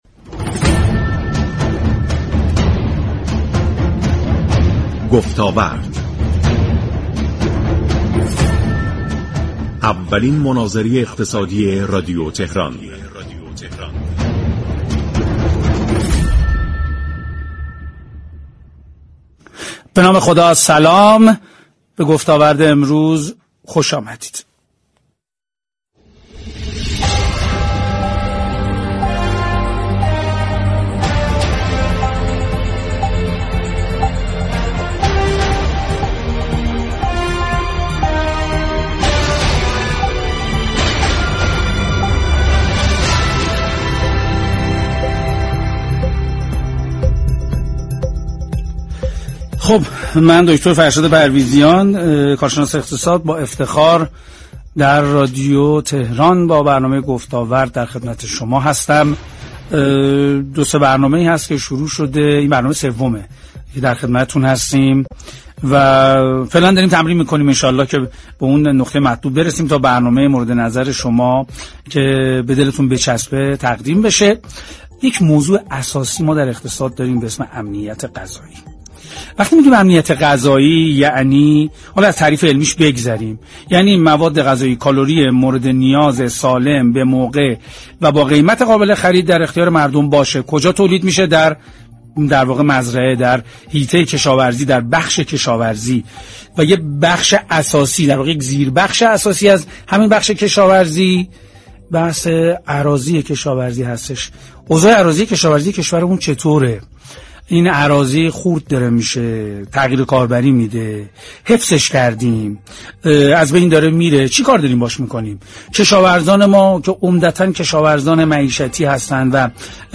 به گزارش پایگاه اطلاع رسانی رادیو تهران؛ «امنیت غذایی» موضوعی است كه برنامه گفتاورد 16 مهرماه 1402 رادیو تهران در مناظره ای اقتصادی به آن پرداخت.